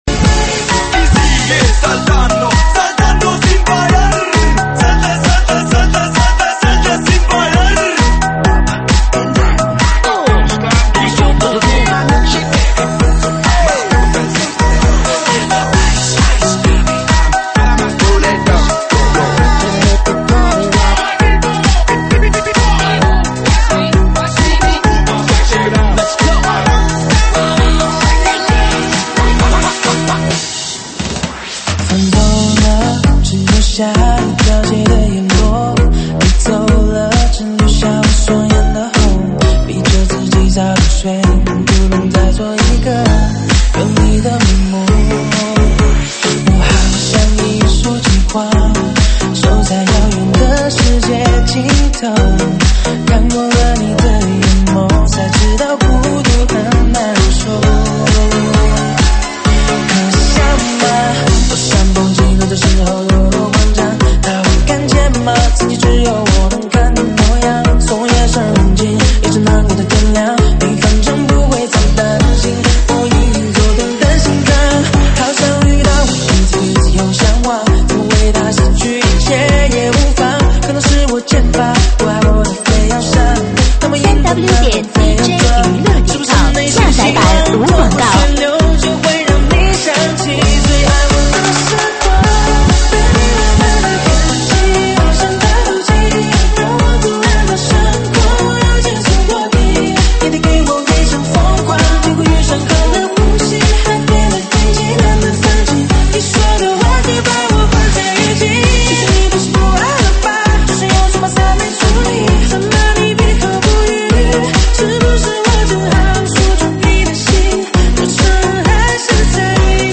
(现场串烧)
舞曲类别：现场串烧